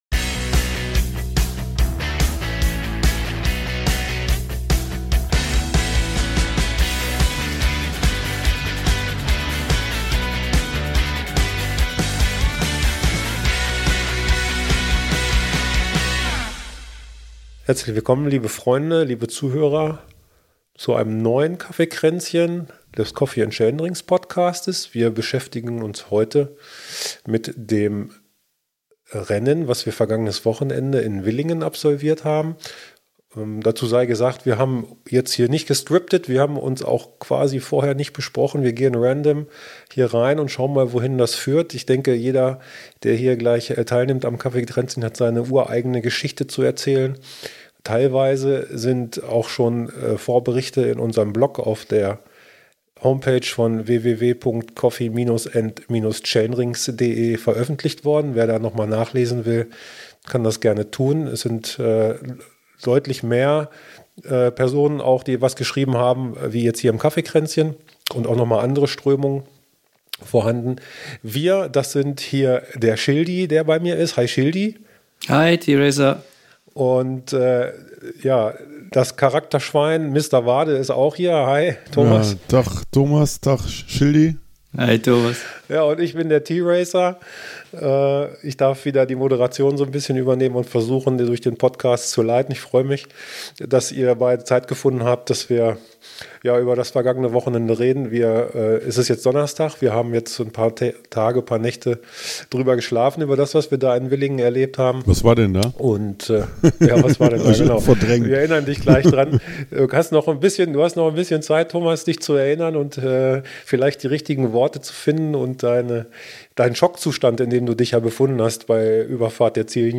hat zum Interview gebeten